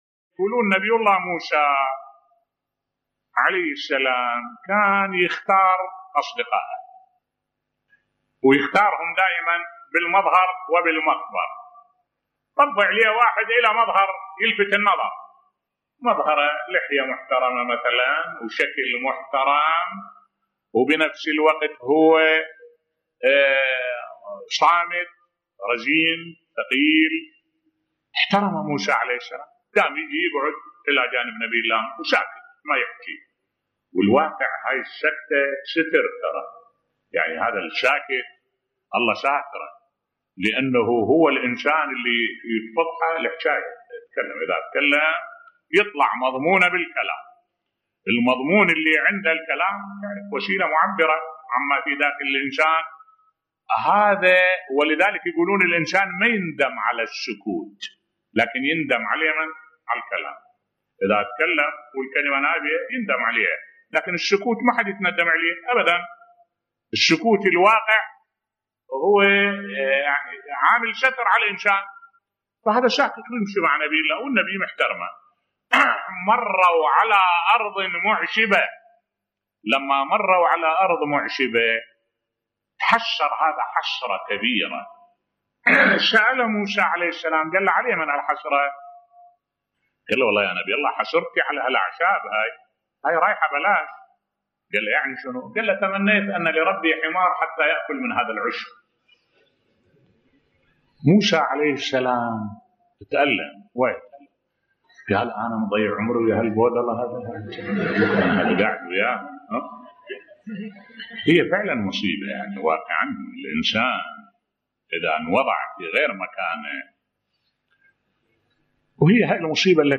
ملف صوتی الصمت ستر للإنسان ويكسب المحبة بصوت الشيخ الدكتور أحمد الوائلي